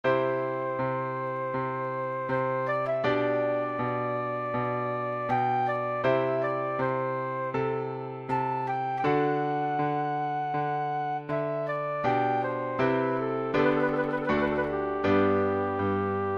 Přednesová skladba pro zobcovou flétnu
Cena obsahuje: sólový part pro zobcovou flétnu, klavírní doprovod, soubor MP3, soubor MID, zaslání na emailovou adresu uvedenou v objednávce.